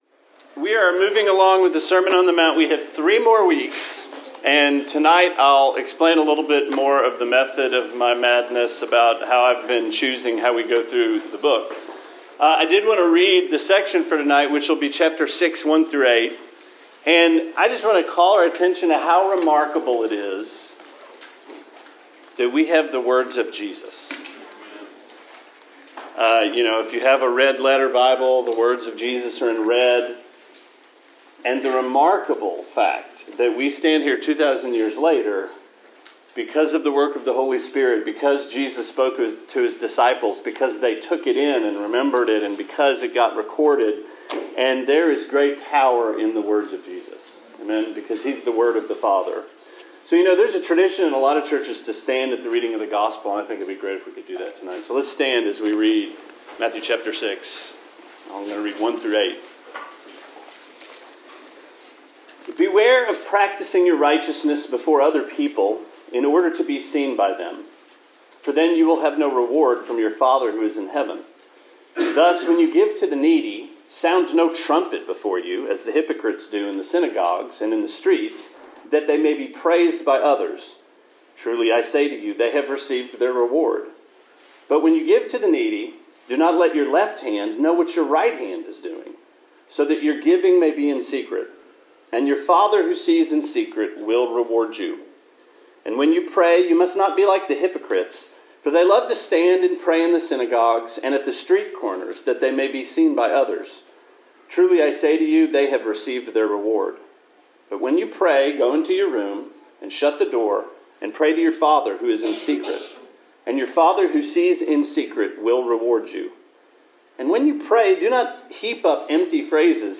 Sermon 2/21: Sermon on the Mount: Introduction to the Lord’s Prayer